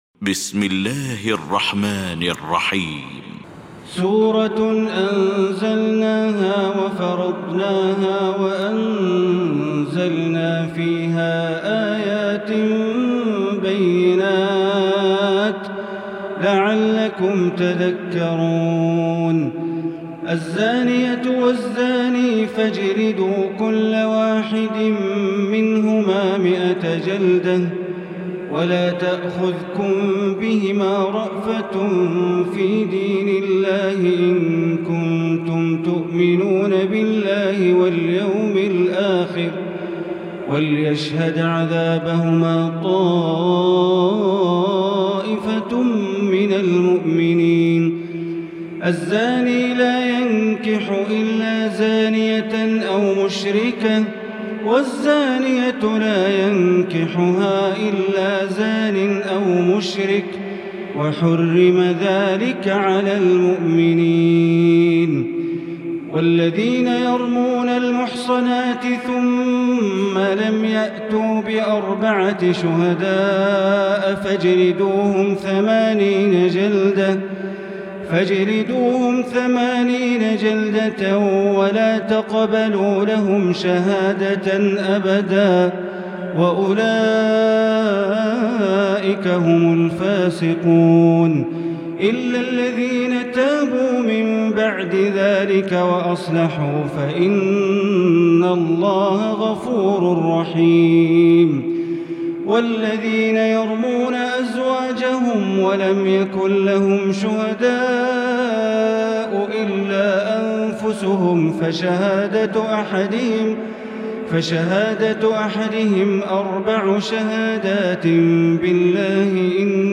المكان: المسجد الحرام الشيخ: معالي الشيخ أ.د. بندر بليلة معالي الشيخ أ.د. بندر بليلة النور